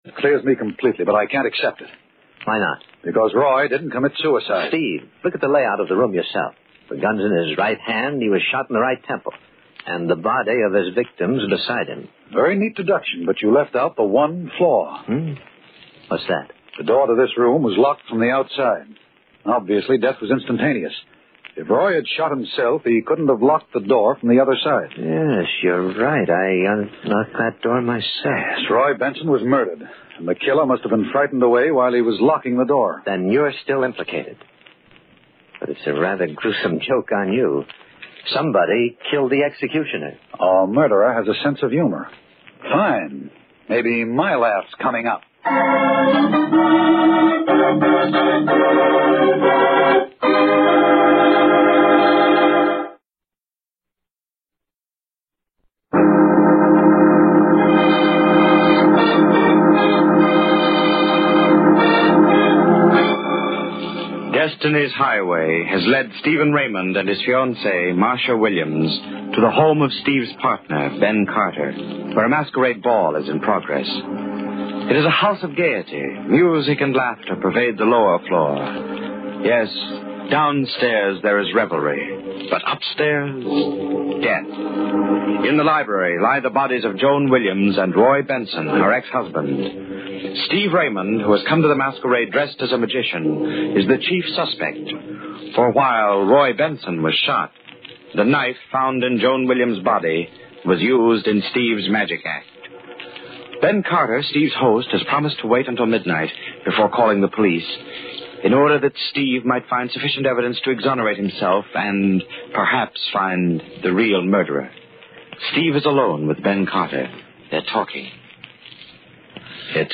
Tags: Radio Horror Mystery Radio Show The Haunting Hour